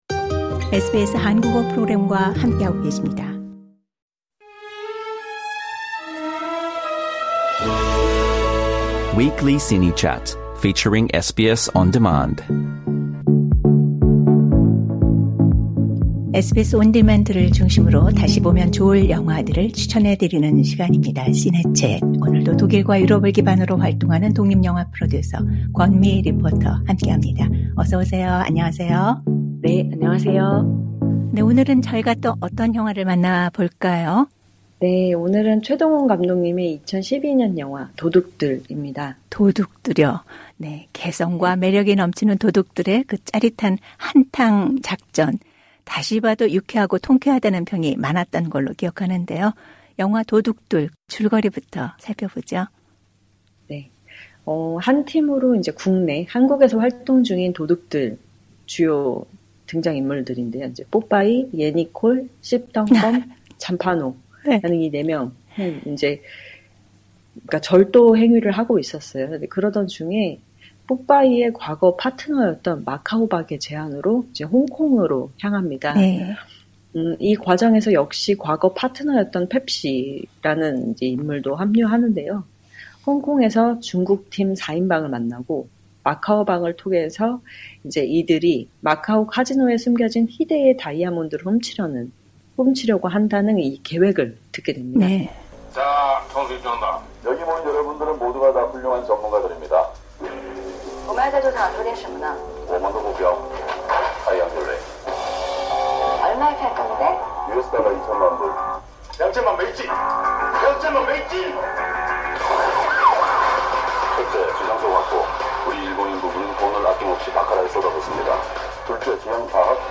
Trailer Audio Clip 이렇게 모인 10명의 도둑들은 이번 일을 일생일대의 기회로 생각하며 마카오 박의 지시에 따라 카지노에 잠입해 다이아몬드를 가진 티파니에게 접근을 시도합니다.